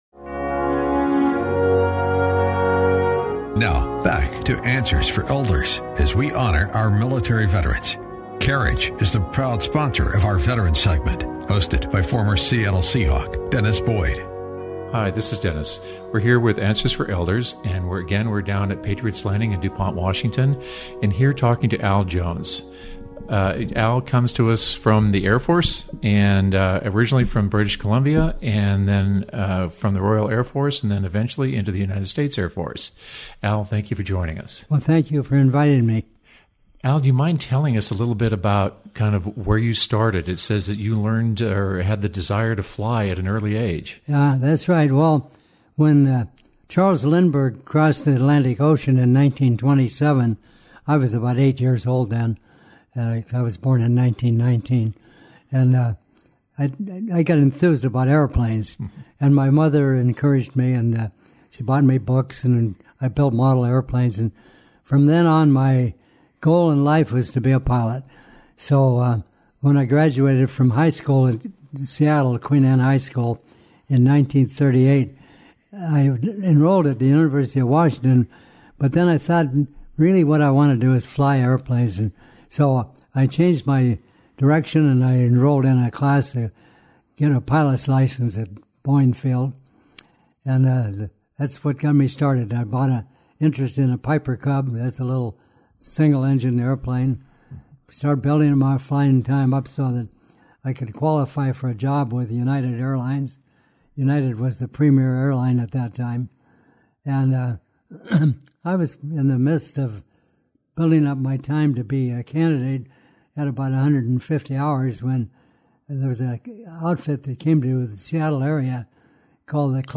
Veterans Interview